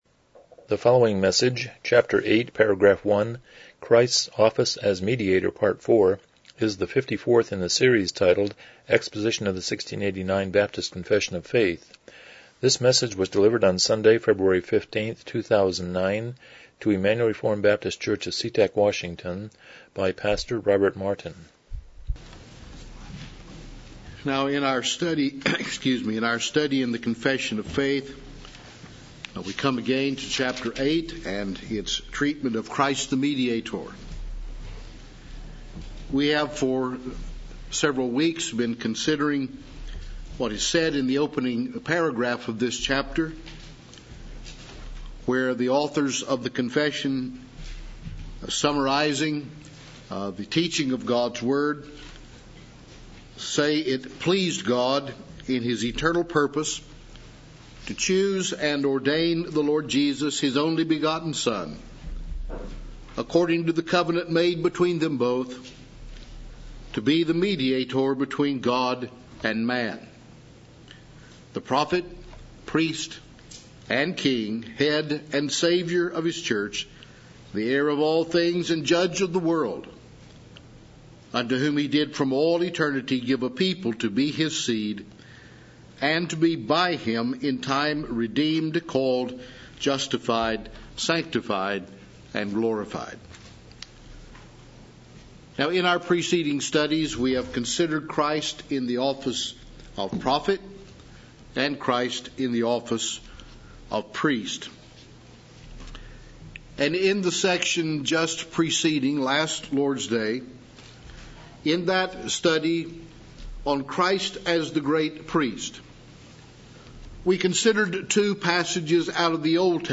1689 Confession of Faith Service Type: Evening Worship « 72 Romans 6:3 22 God’s Response to Man’s Sin